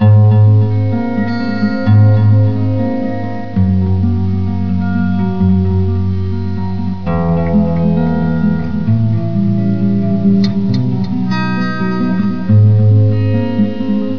Chorus in WAV format